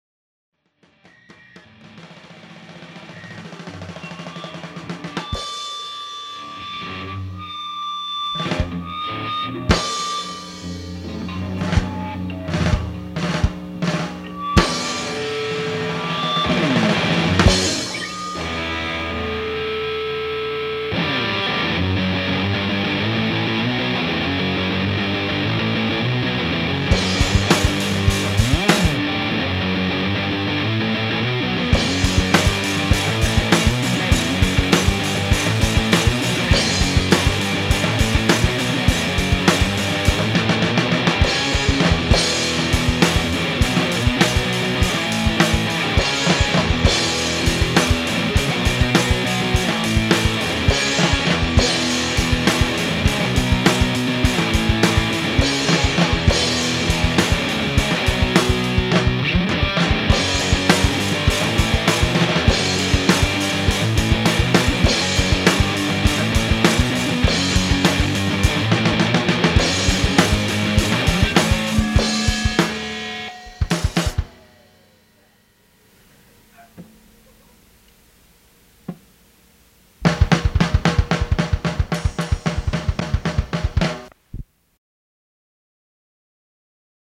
An excerpt from an early rehearsal